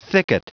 Prononciation du mot thicket en anglais (fichier audio)
Prononciation du mot : thicket